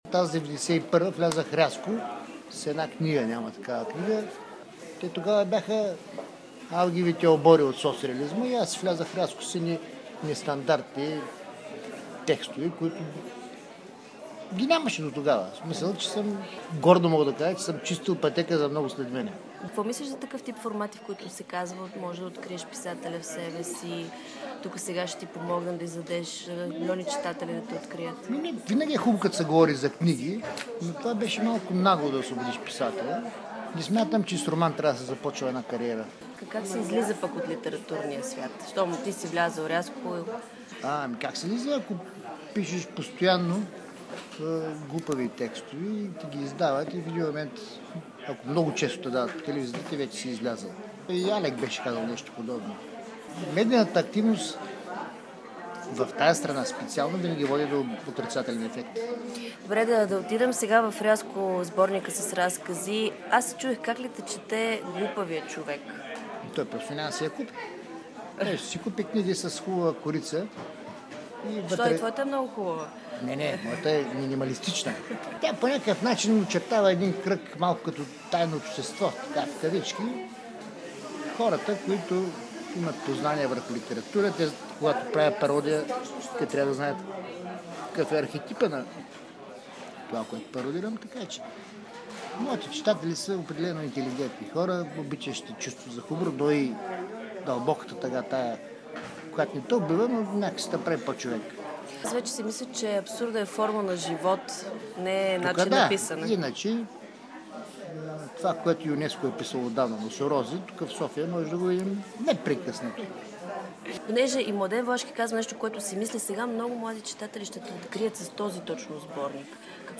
Малко преди да започне премиерата, той отговори специално за Бинар на няколко въпроса. Какво мисли за риалити форматите за писатели, вярва ли на мечтата за здравна реформа и как иска да го наричат – разберете от звуковия файл!